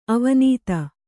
♪ avanīta